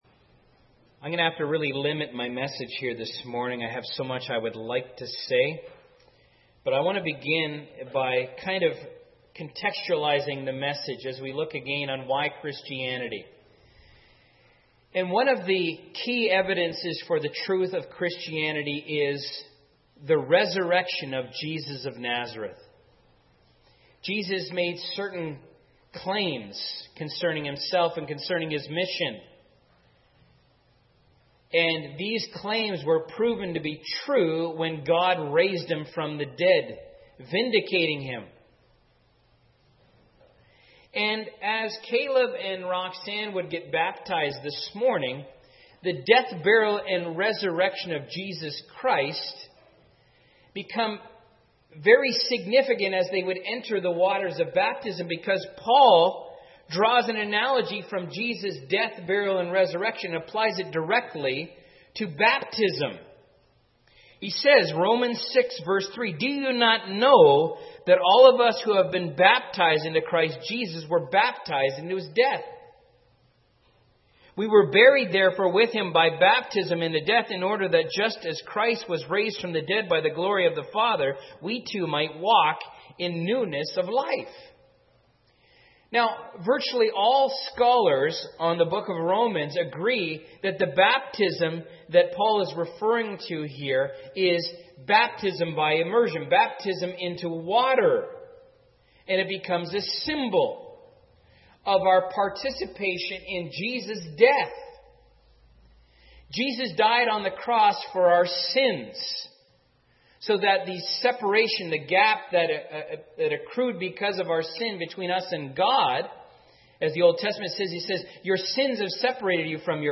Why Christianity Audio Sermons